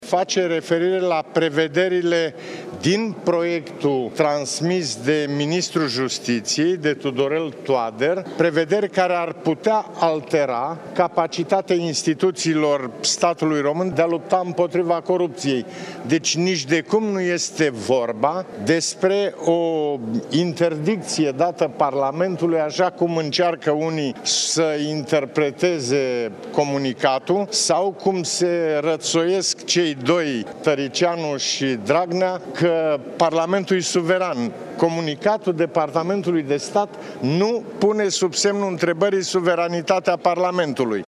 “România este în situaţia în care riscă să deterioreze nişte relaţii pentru o proastă şi defectuasă înţelegere a modului în care lucrează Guvernul SUA. Aş pleca de la conţinutul comunicatului purtătorului de cuvânt al Departamentului de stat. Prin acest comunicat nu se interzice discutarea acestor legi, ci el are două semnificaţii în analiza mea: este o expresie a neîncrederii şi face parte la prevederile din proiectul transmis de ministrul Justiţiei. Nu este vorba despre vreo interdicţie dată Parlamentului, aşa cum încearcă unii să interpreteze comunicatul sau cum se răţoiesc Tăriceanu şi Dragnea”, a declarat Băsescu la Parlament.